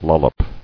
[lol·lop]